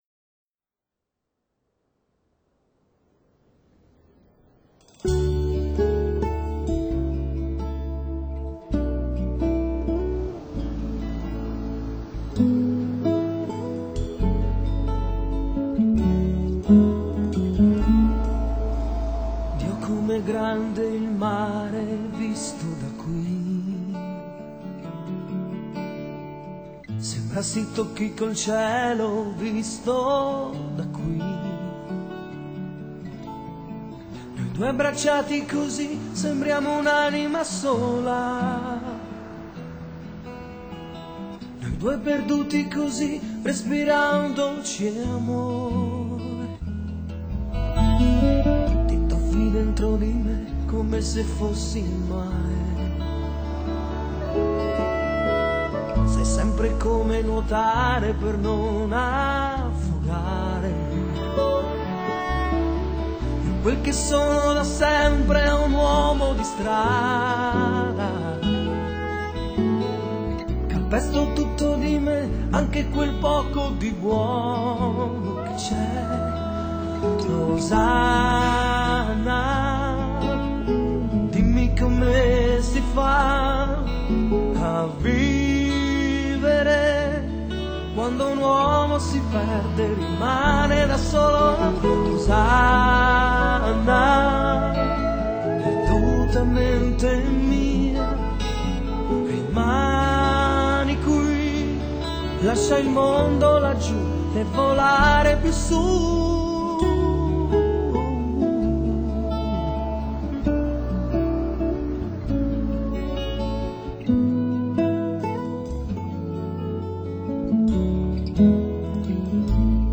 现代的意大利带著古典兼流行的姿态站在舞台上，台上传来的歌曲融合了古典与流行，但意大利式的热情依旧。